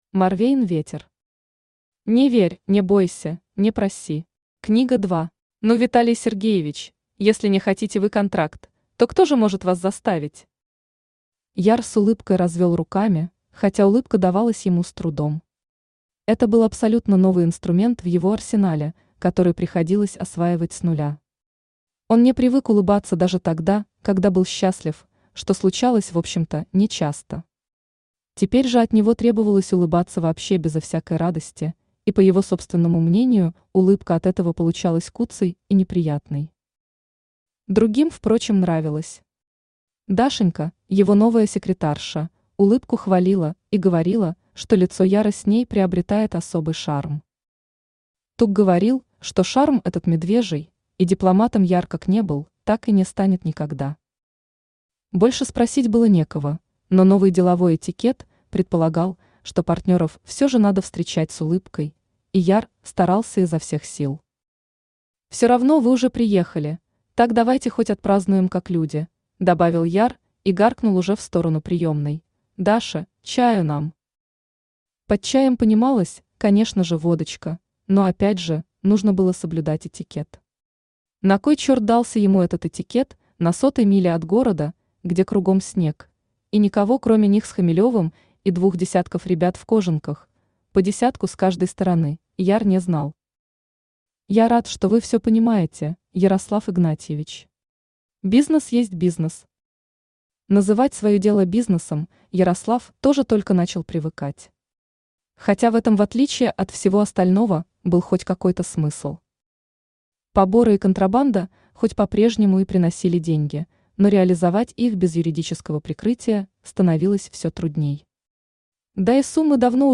Аудиокнига Не верь, не бойся, не проси. Книга 2 | Библиотека аудиокниг
Aудиокнига Не верь, не бойся, не проси. Книга 2 Автор Морвейн Ветер Читает аудиокнигу Авточтец ЛитРес.